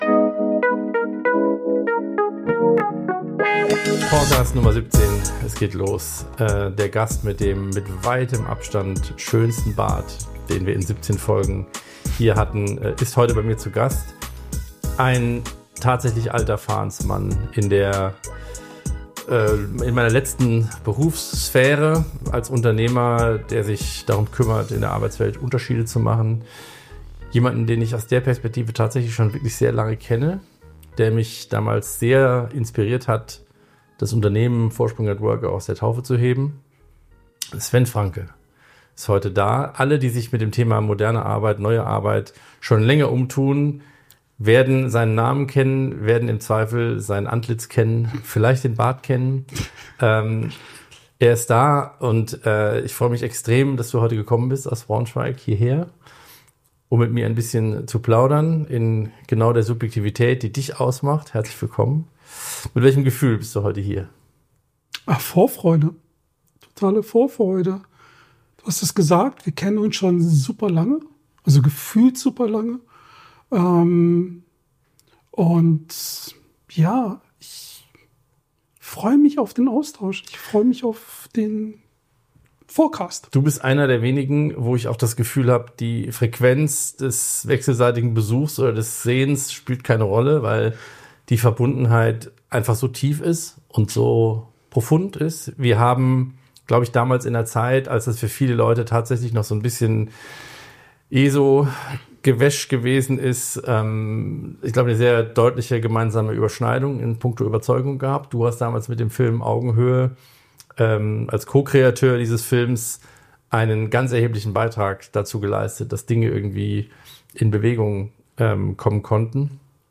Gemeinsam blicken sie auf die Anfänge der New-Work-Bewegung, diskutieren über hartnäckige Strukturen in Unternehmen, Gehaltstransparenz, faire Vergütung und die Frage, was Organisationen brauchen, um morgen noch relevant zu sein. Ein ehrliches, tiefes und richtungsweisendes Gespräch über Mut, Wandel und die Zukunft der Arbeit.